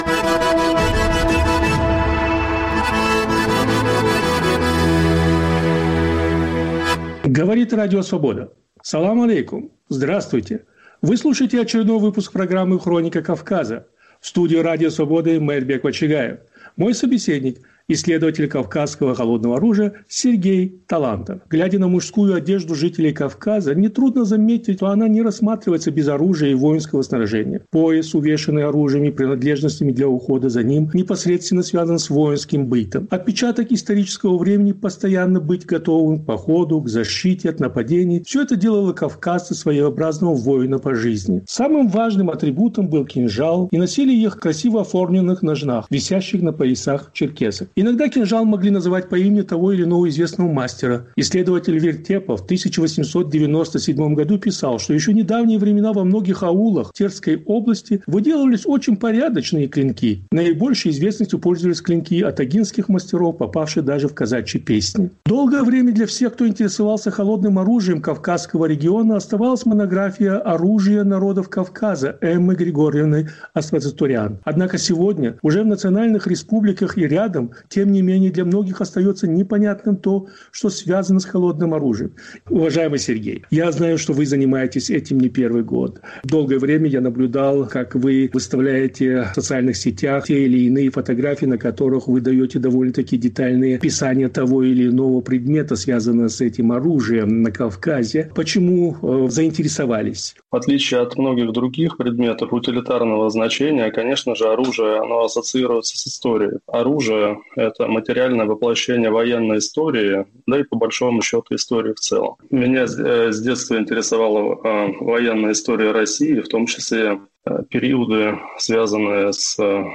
беседа с исследователем кинжалов и шашек